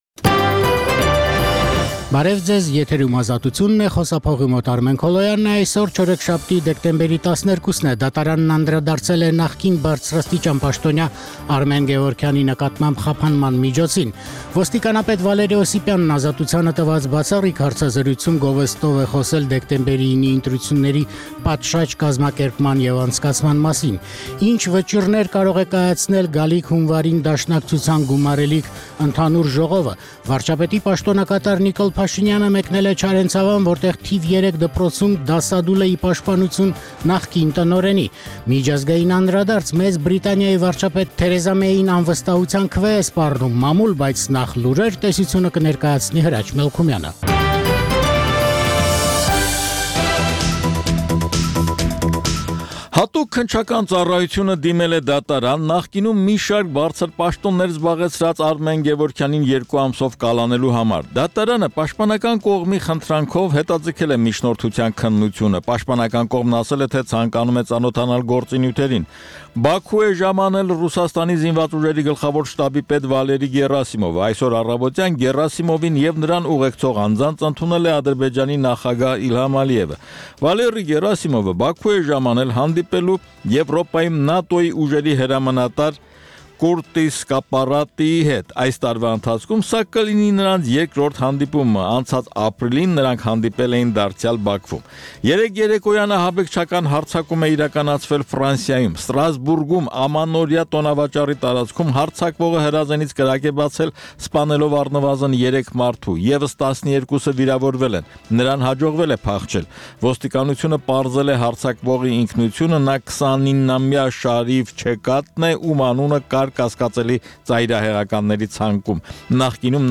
Տեղական եւ միջազգային լուրեր, երիտասարդությանը առնչվող եւ երիտասարդությանը հուզող թեմաներով ռեպորտաժներ, հարցազրույցներ, երիտասարդական պատմություններ, գիտություն, կրթություն, մշակույթ: